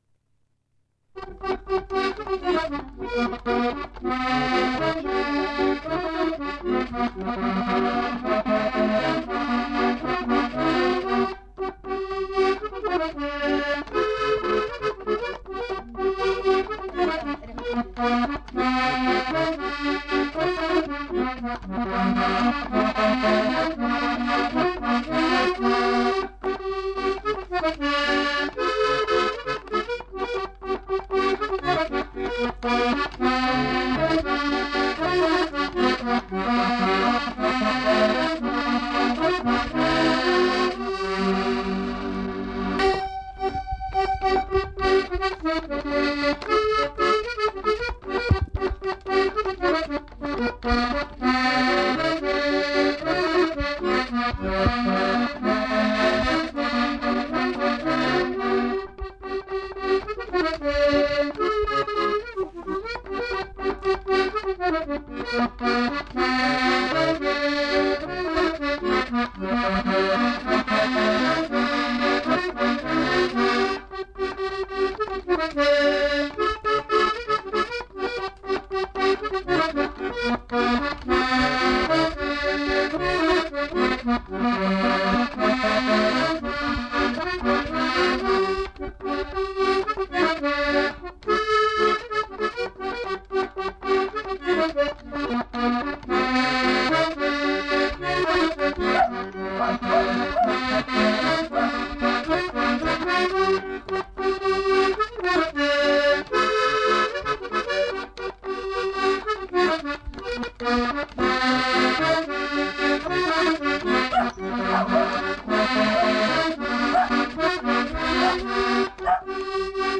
Aire culturelle : Savès
Genre : morceau instrumental
Instrument de musique : accordéon diatonique
Danse : rondeau